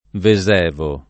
Vesevo [ ve @$ vo ]